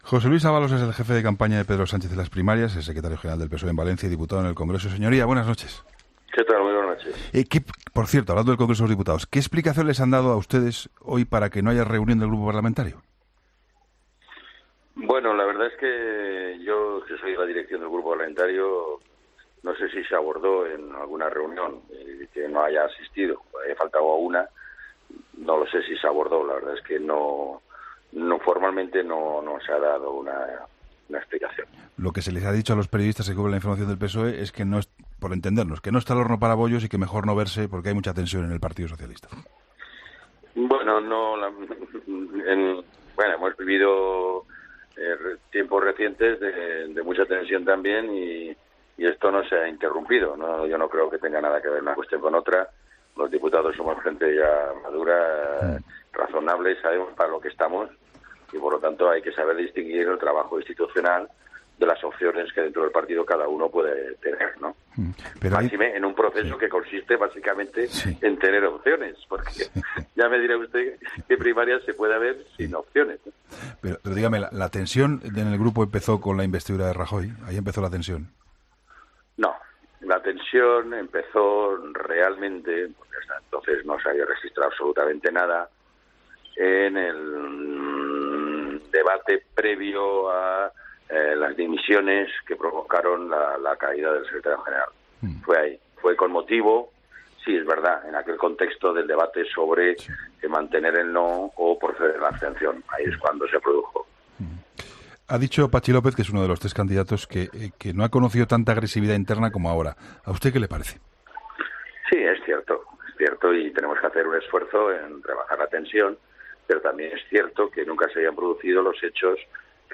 Entrevista a José Luis Ábalos